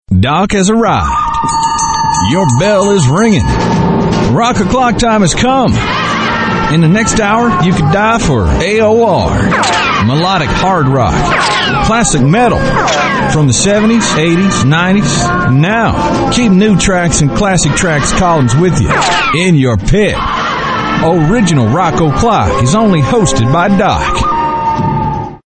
RADIO IMAGING / ROCK ALTERNATIVE /